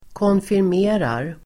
Uttal: [kånfirm'e:rar]